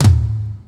• '00s Reggae Tom Sample F# Key 18.wav
Royality free tom one shot tuned to the F# note. Loudest frequency: 609Hz
00s-reggae-tom-sample-f-sharp-key-18-S3A.wav